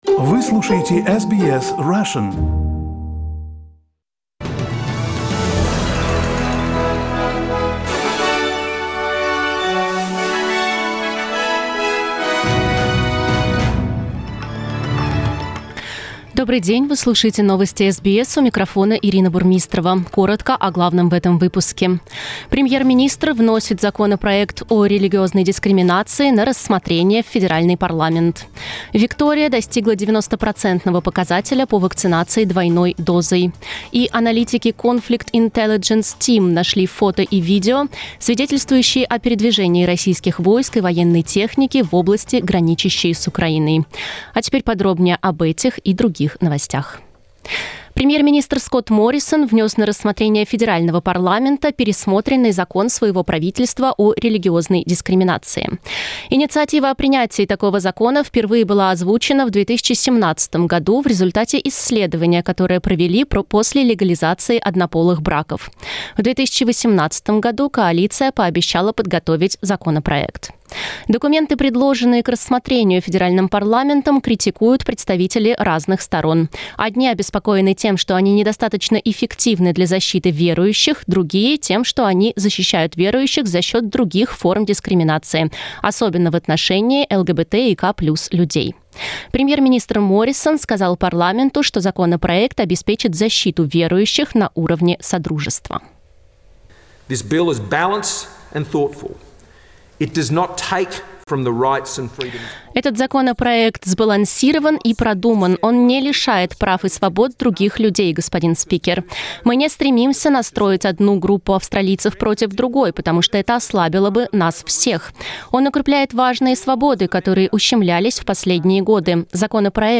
Новости SBS на русском языке - 25.11